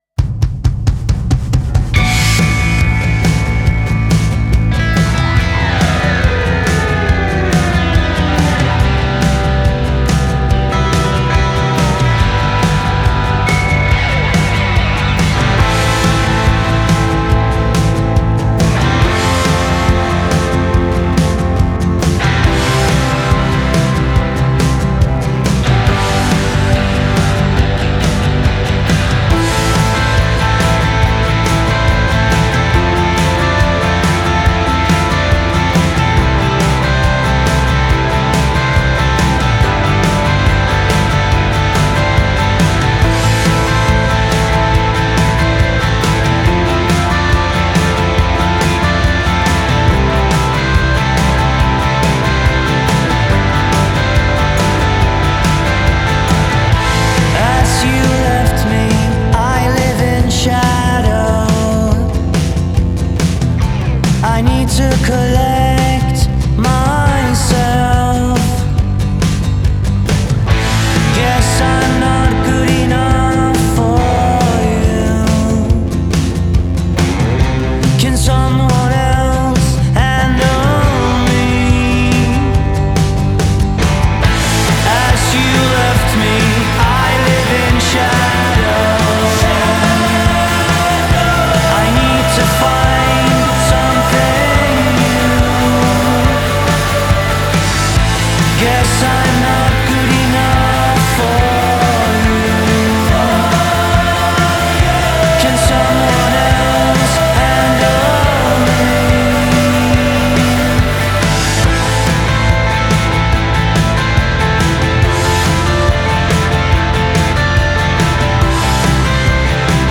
an introspective, melodic and mature Rock-sound